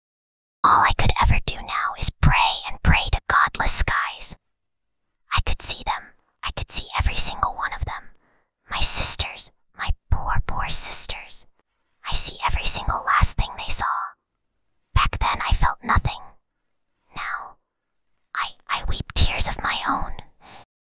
File:Whispering Girl 5.mp3
Whispering_Girl_5.mp3